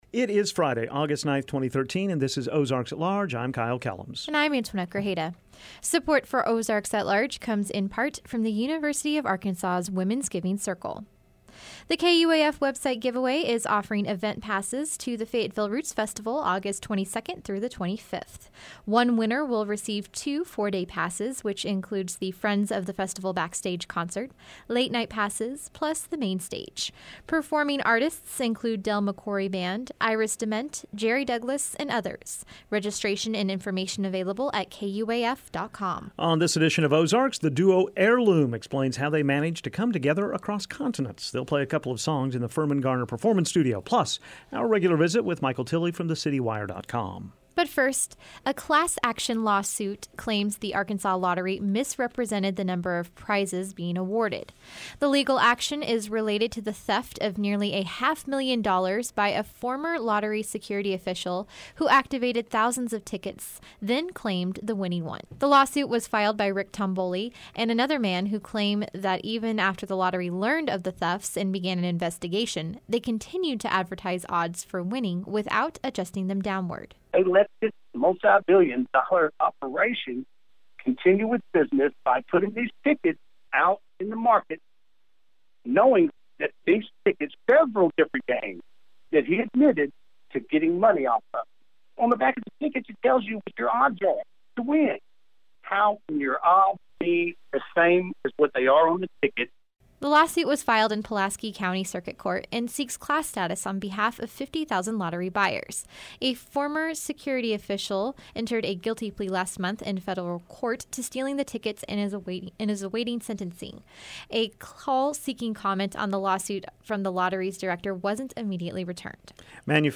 They’ll play a couple of songs in the Firmin-Garner Performance Studio.